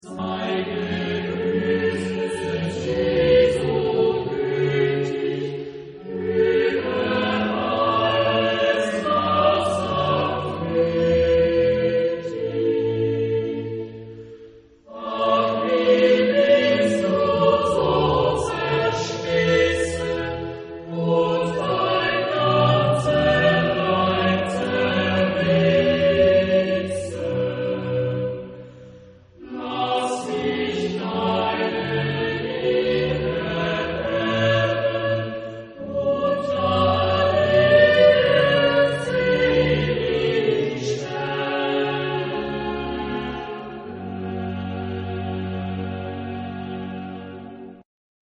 Genre-Style-Forme : Sacré ; Baroque ; Choral
Type de choeur : FH OU SB  (2 voix mixtes )
Tonalité : sol mineur